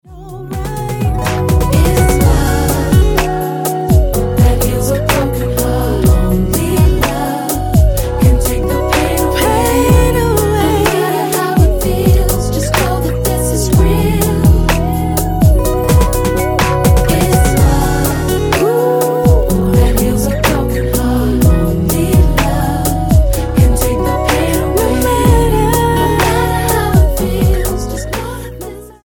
Style: R&B